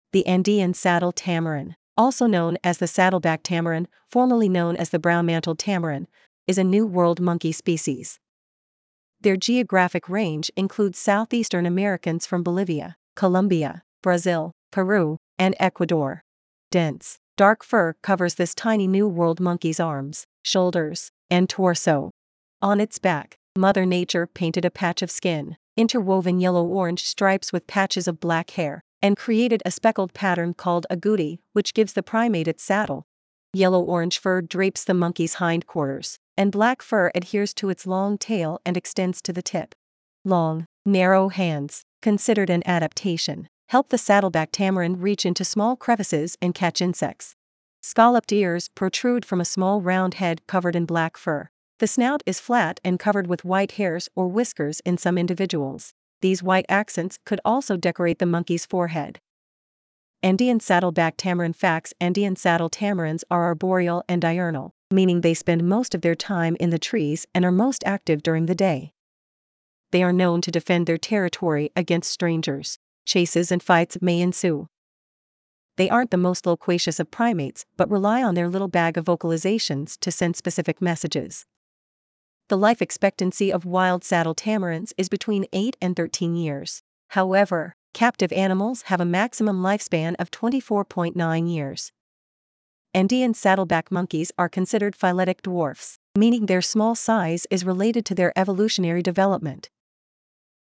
Andean Saddle-back Tamarin
• They aren’t the most loquacious of primates but rely on their little bag of vocalizations to send specific messages.
Andean-Saddle-back-Tamarin.mp3